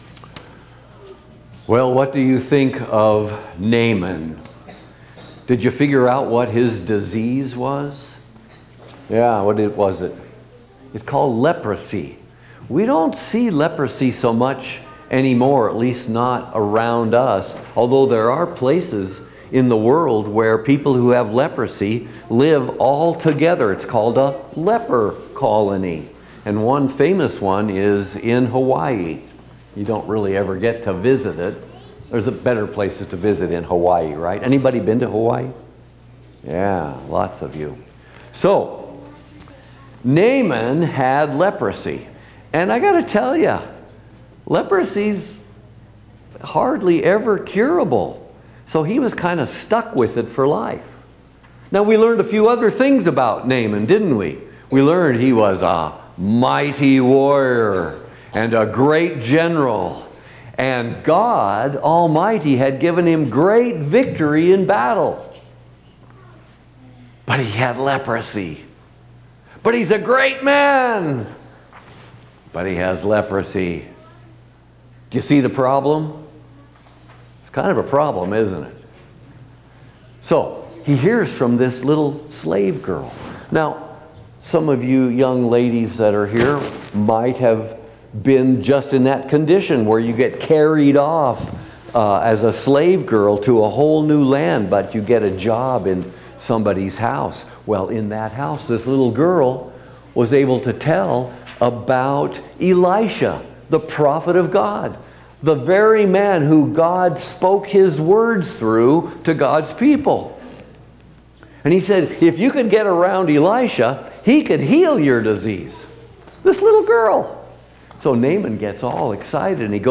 Midweek Lenten Service with Chapel&nbsp